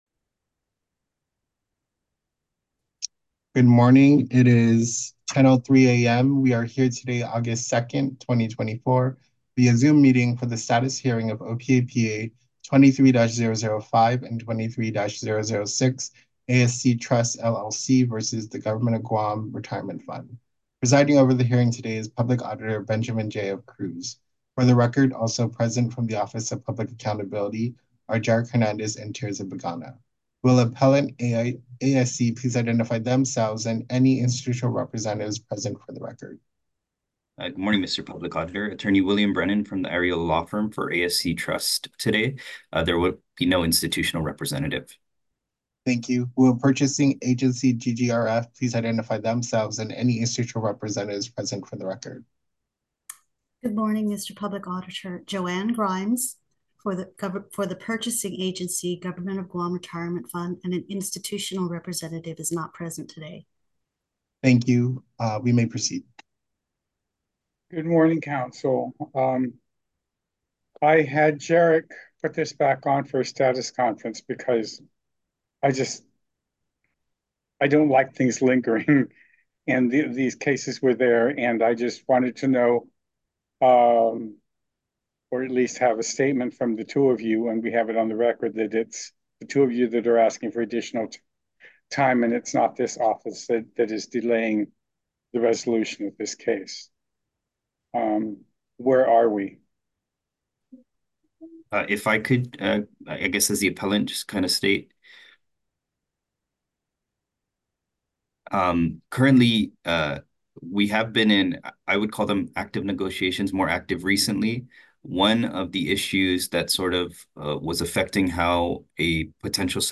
Status Hearing - August 2, 2024